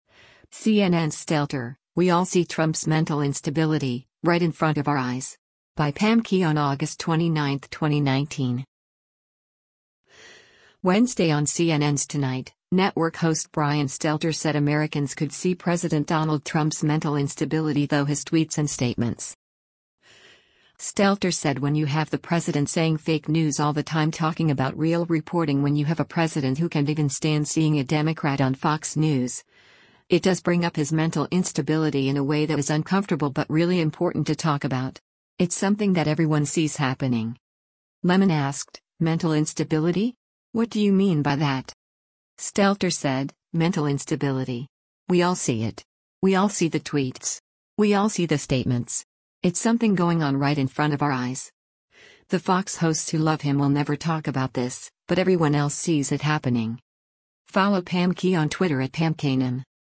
Wednesday on CNN’s “Tonight,” network host Brian Stelter said Americans could see President Donald Trump’s “mental instability” though his tweets and statements.